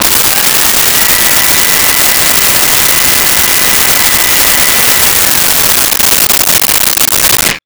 Blender On Liquify
Blender on Liquify.wav